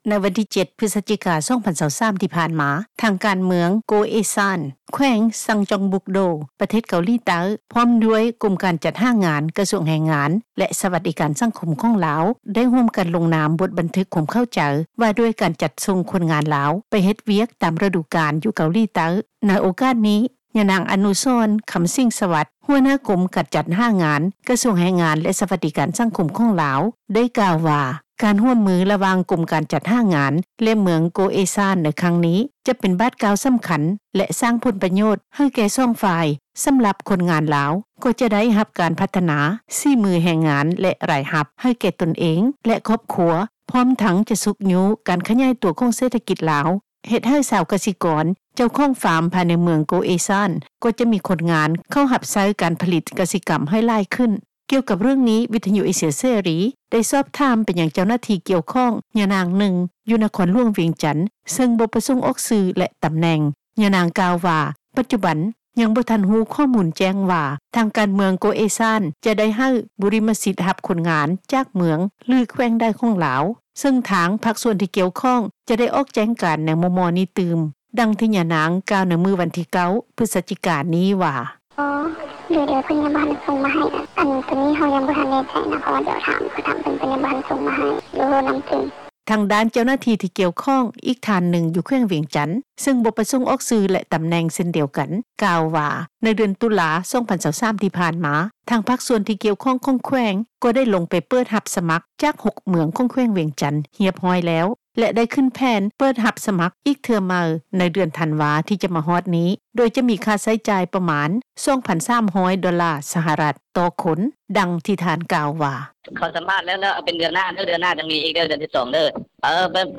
ກ່ຽວກັບເຣຶ່ອງນີ້, ວິທຍຸເອເຊັຽເສຣີ ໄດ້ສອບຖາມ ເຈົ້າໜ້າທີ່ກ່ຽວຂ້ອງ ຍານາງນຶ່ງ ຢູ່ນະຄອນຫຼວງວຽງຈັນ ເຊິ່ງບໍ່ປະສົງອອກຊື່ ແລະ ຕຳແໜ່ງ. ຍານາງກ່າວວ່າ ປັດຈຸບັນ, ຍັງບໍ່ທັນຮູ້ ຂໍ້ມູນຈະແຈ້ງວ່າ ທາງການ ເມືອງໂກເອຊານ ຈະໄດ້ໃຫ້ ບູຣິມະສິດ ຮັບຄົນງານຈາກເມືອງ ຫຼື ແຂວງໃດ ຂອງລາວ, ເຊິ່ງທາງພາກສ່ວນກ່ຽວຂ້ອງ ຈະໄດ້ອອກແຈ້ງການ ໃນມໍ່ໆນີ້ຕື່ມ.